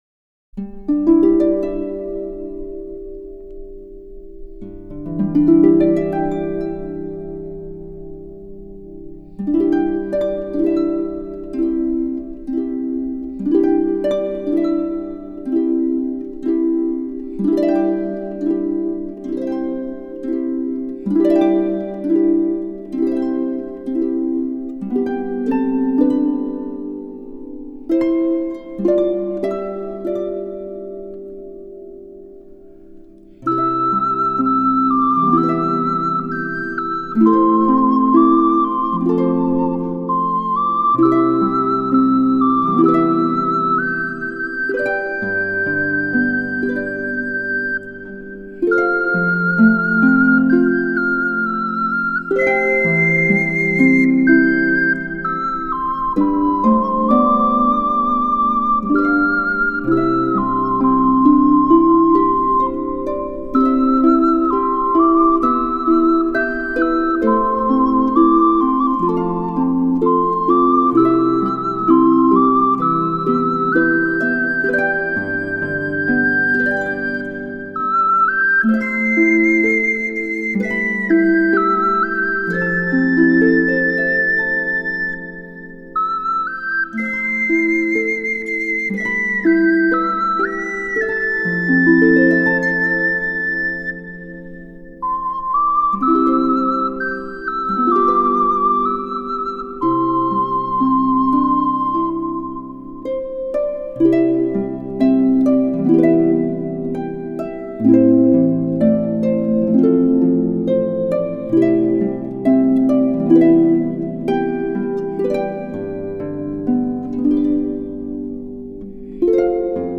Genre: Age New.